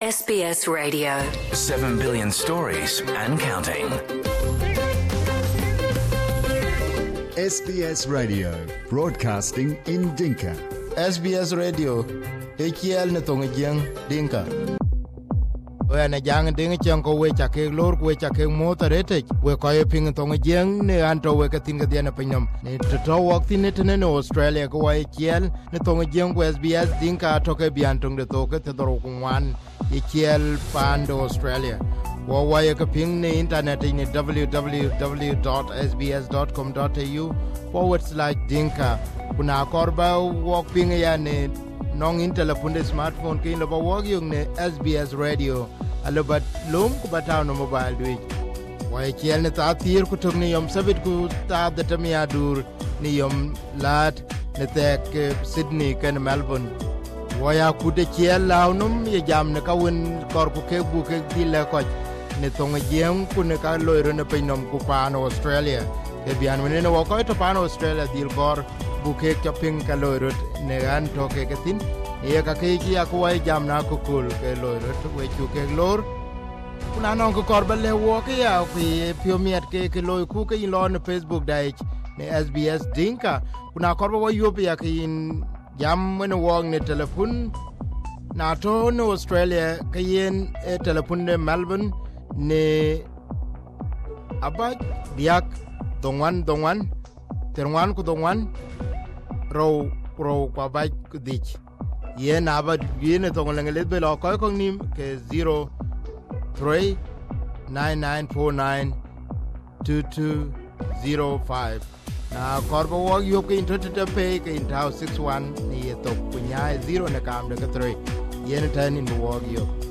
will go one on one with the former President in this part one. Mengistu still lives in exile and still wanted by Ethiopia on human rights abuses during his times. in South Sudan; he is considered a hero.